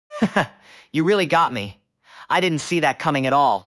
auto 모드 [laughter]는 실제로 문장 중간에 웃음이 섞여 나왔어요.
nonverbal/00_auto_laughter_mid — auto 모드 [laughter] 정상 발화
nonverbal_00_auto_laughter_mid.wav